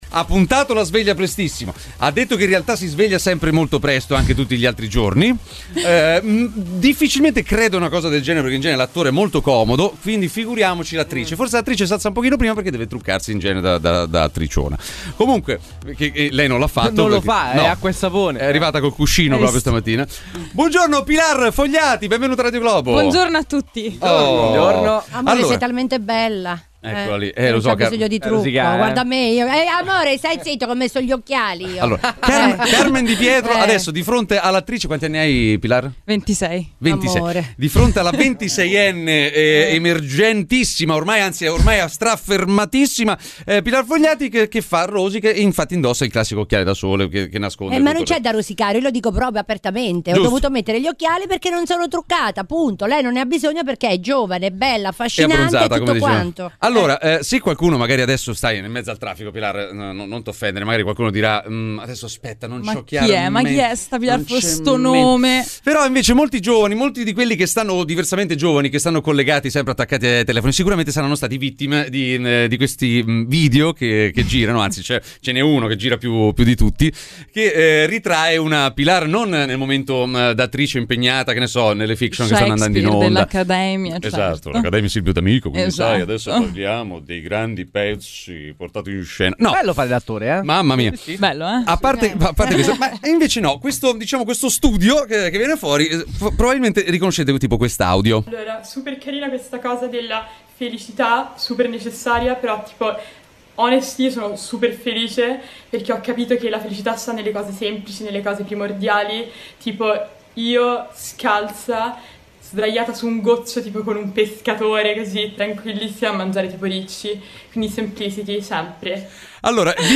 Pilar Fogliati ospite al Morning Show - Radio Globo
Umorismo, attualità, aggiornamenti sul traffico in tempo reale e l'immancabile contributo degli ascoltatori di Radio Globo, protagonisti con telefonate in diretta e note vocali da Whatsapp.